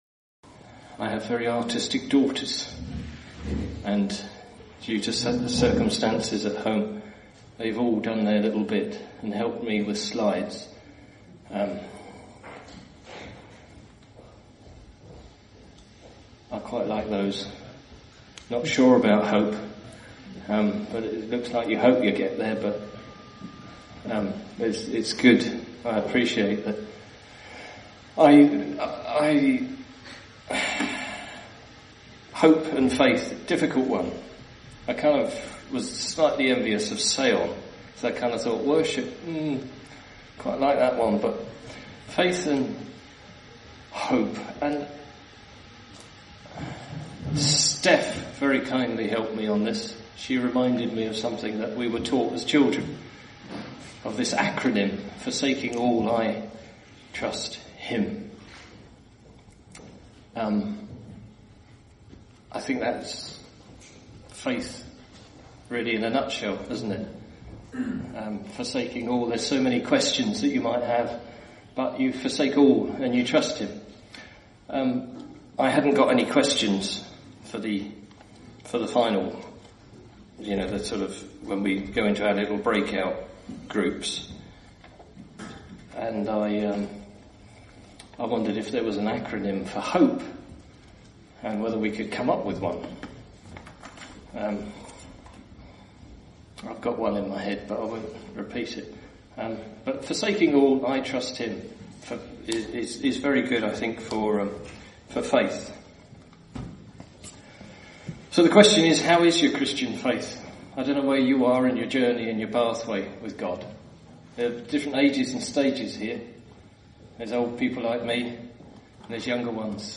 This year at Refresh 2024 we looked at the subject of Reality and what it means to be a real Christian. In this talk, you we will look at the reality of Faith & Hope for the believer in the Lord Jesus.